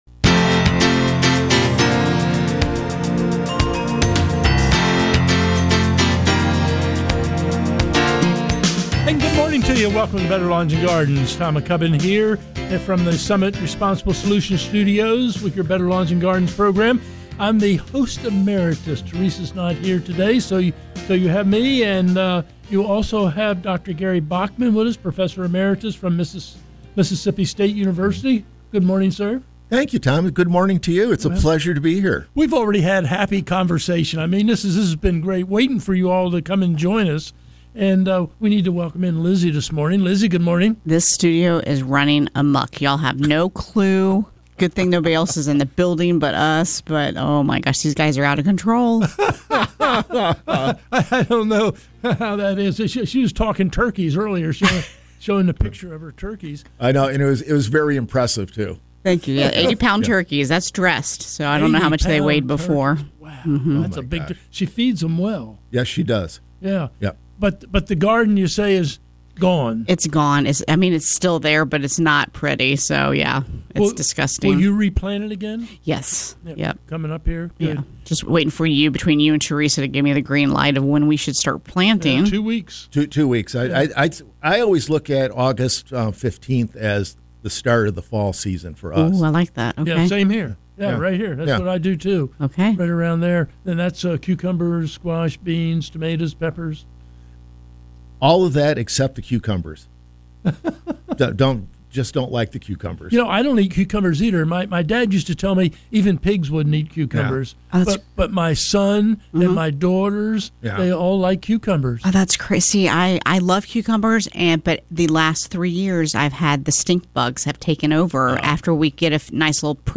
Better Lawns and Gardens Hour 1 – Coming to you from the Summit Responsible Solutions Studios.